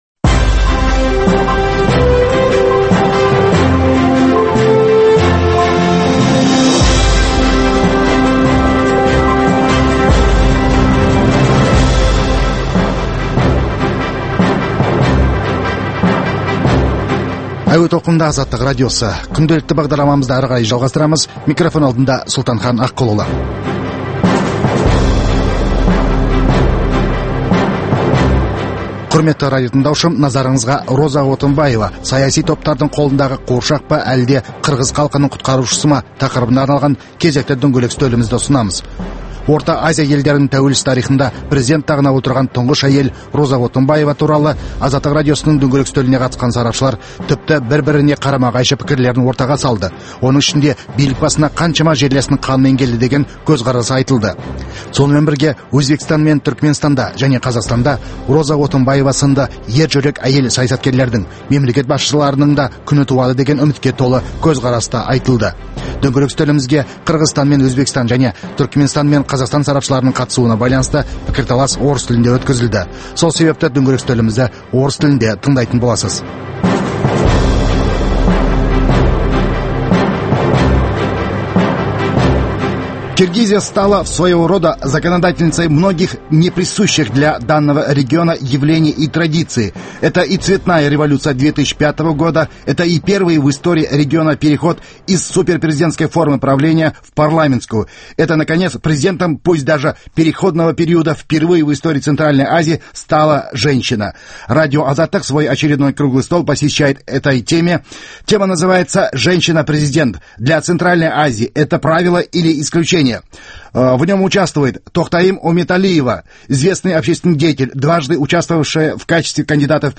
Дөңгелек үстел сұхбаты